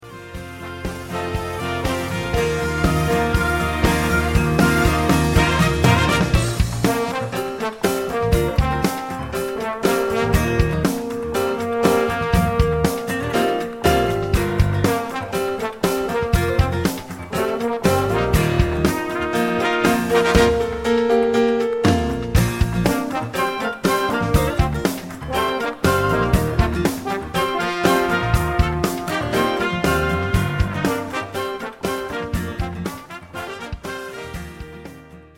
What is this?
Voicing: Orch,Enhan